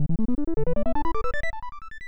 login.wav